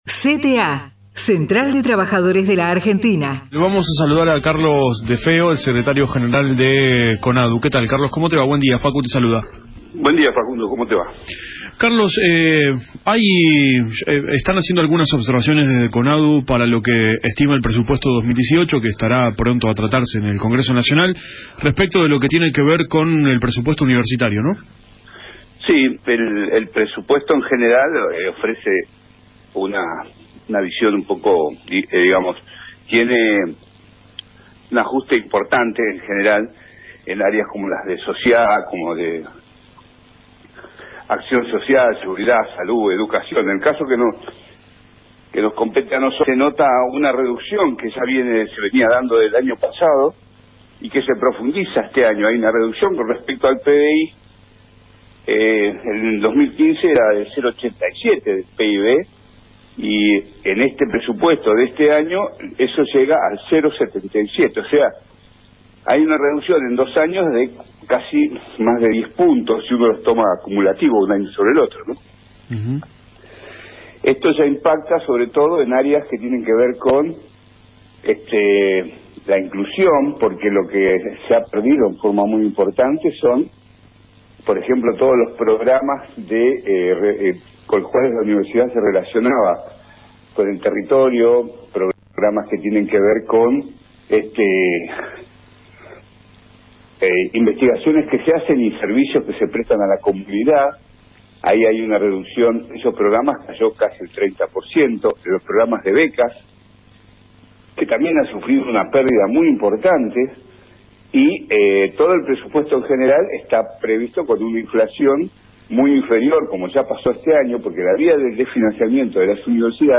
entrevista FM EN-TRANSITO